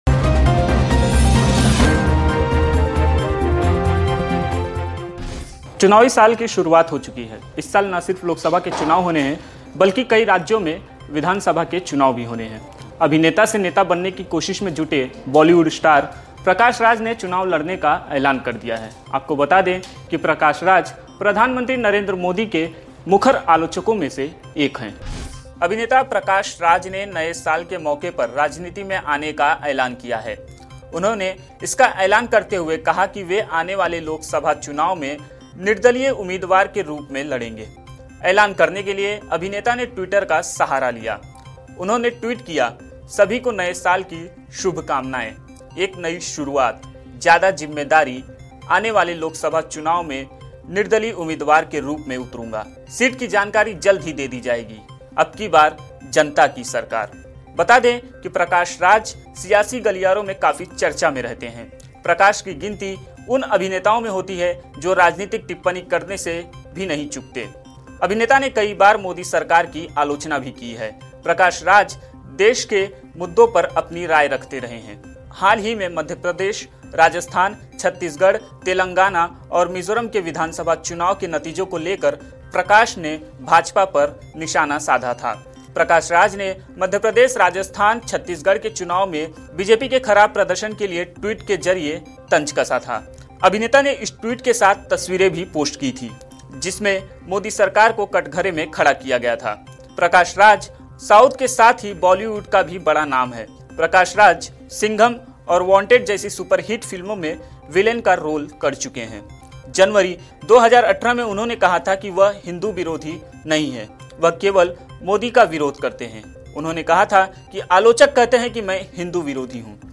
न्यूज़ रिपोर्ट - News Report Hindi / प्रधानमंत्री नरेंद्र मोदी के आलोचक अभिनेता प्रकाश राज लड़ेंगे लोकसभा चुनाव